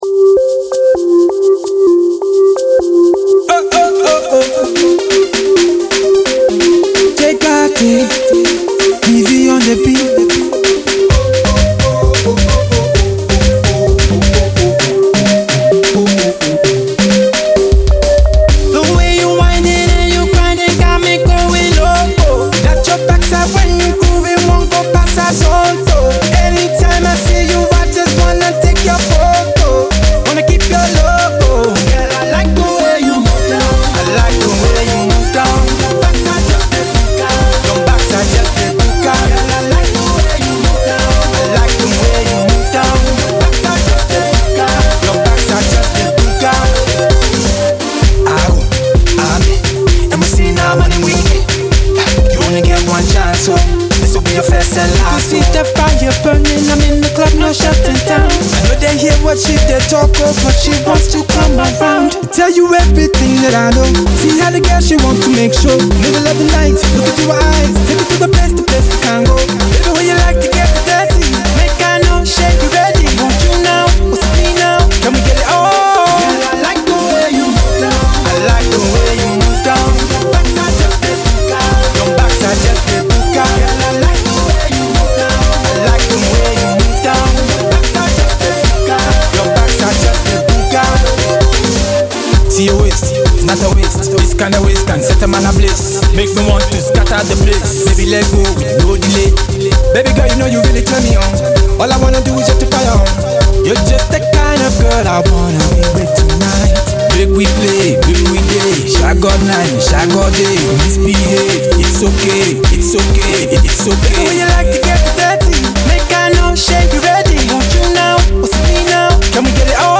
Dance-infused Afro Pop sound, catchy melodies
club jam
that is sure to get you in the zone for the dance floor.